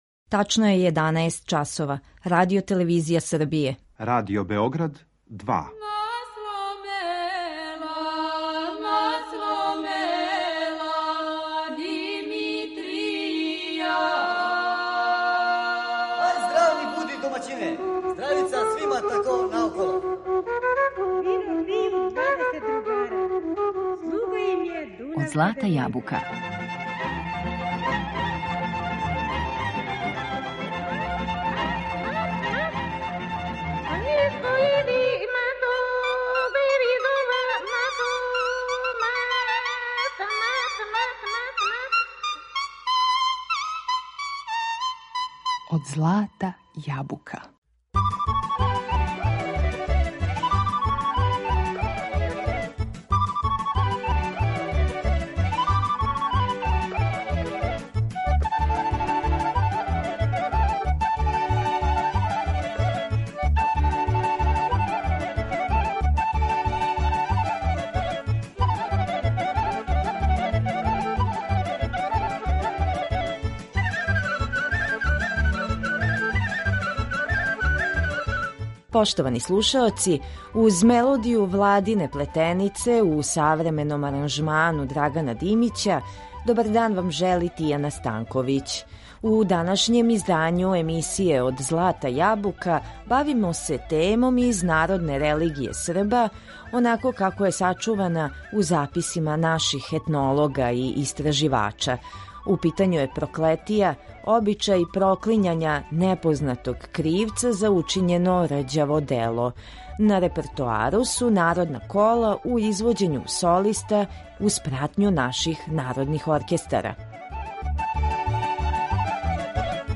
У питању је проклетија, обичај проклињања непознатог кривца за учињено недело. На репертоару данашње емисије су мелодија народних игара у извођењу солиста и народних оркестара.